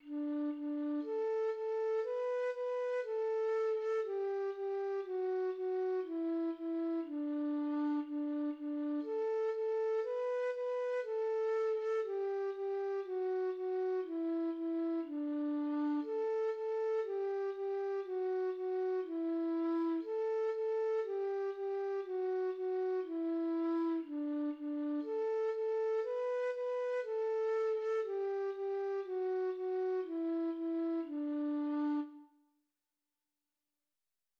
Műfaj népdal
Hangfaj dúr
A kotta hangneme D dúr